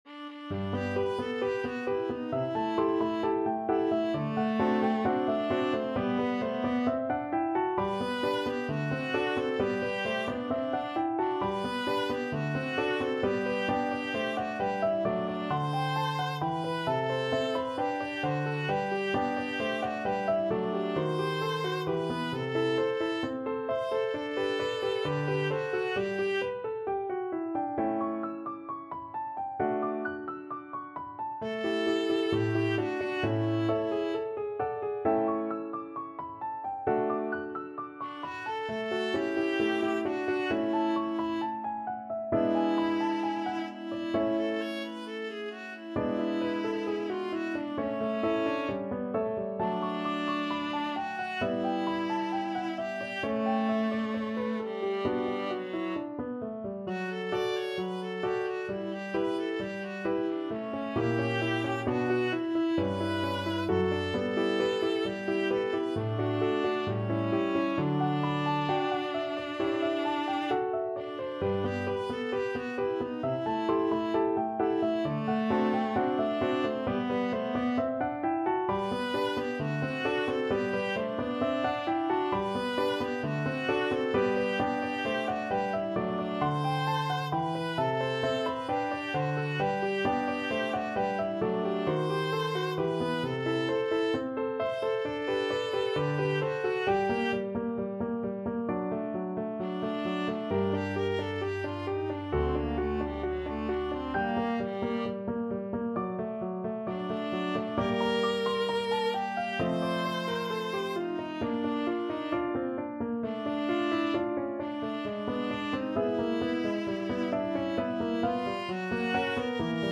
Viola
G major (Sounding Pitch) (View more G major Music for Viola )
=132 Allegro assai (View more music marked Allegro)
Classical (View more Classical Viola Music)